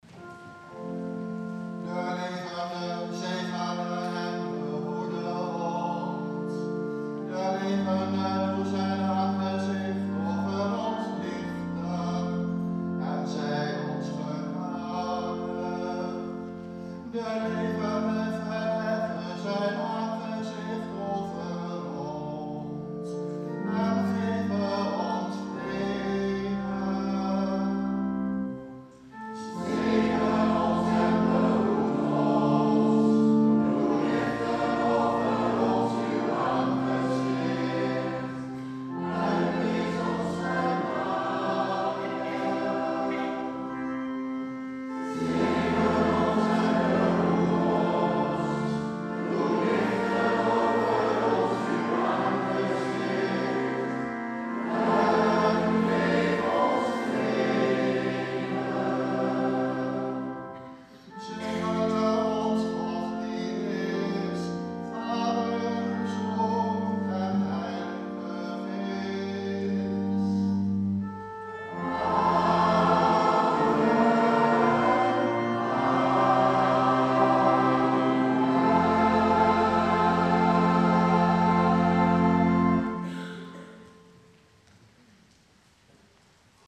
Oecumenische viering in de H. Nicolaaskerk